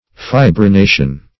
Search Result for " fibrination" : The Collaborative International Dictionary of English v.0.48: Fibrination \Fi`bri*na"tion\, n. (Med.) The state of acquiring or having an excess of fibrin.